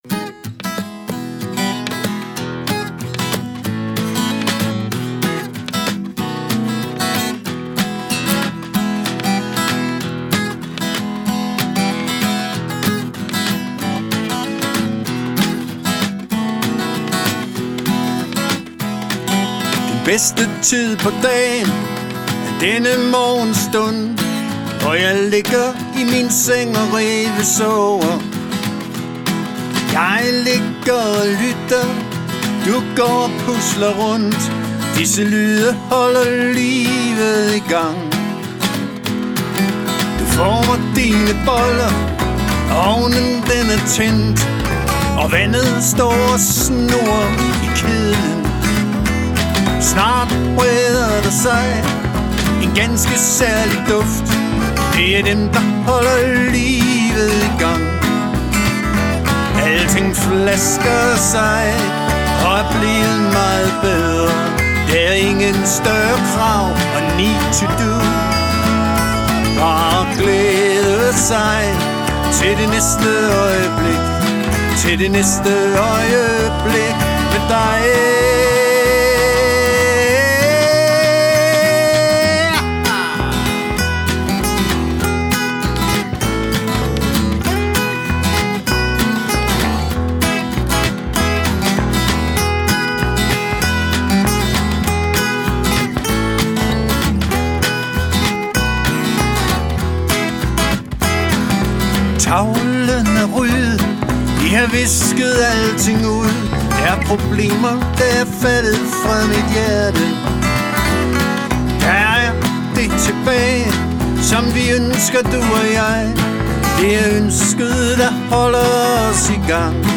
Vi er en swingende duo, der gennem mange år har præget den danske musikscene. Vi er to guitarister.
Også her benytter vi os af backtracks, så musikken fylder lidt mere.